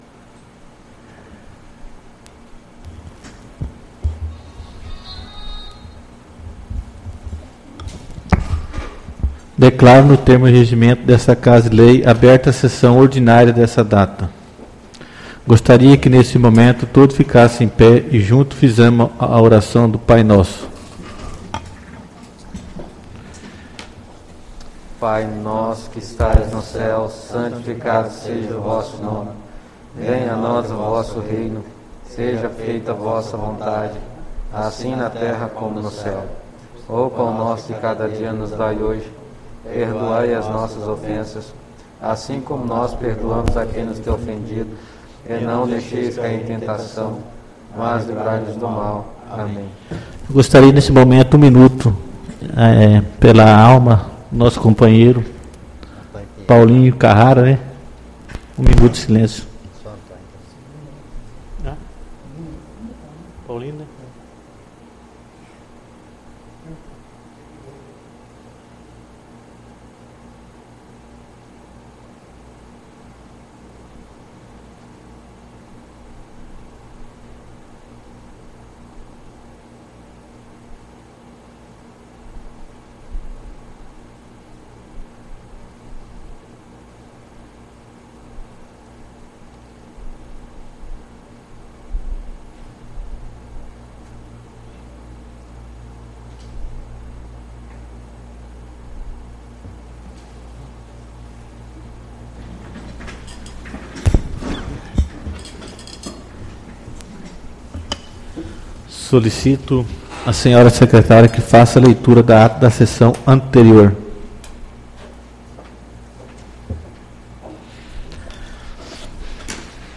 SESSÃO ORDINÁRIA DO DIA 02/06/2020